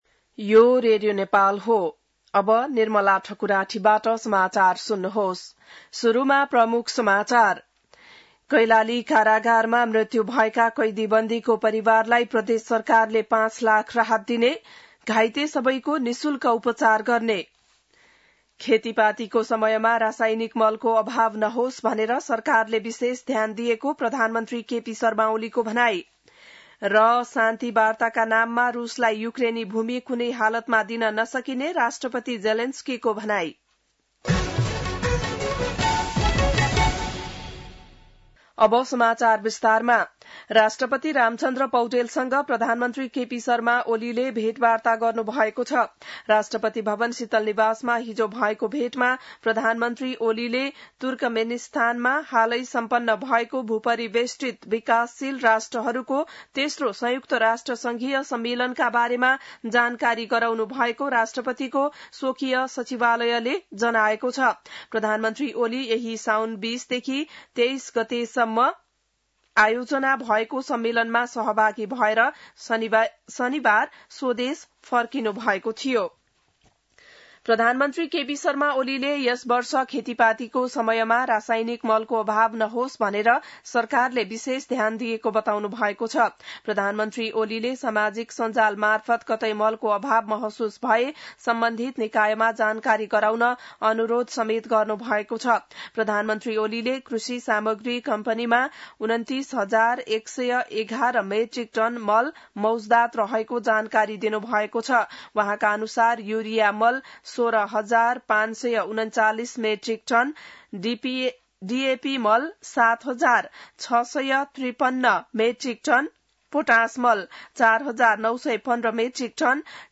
बिहान ९ बजेको नेपाली समाचार : २६ साउन , २०८२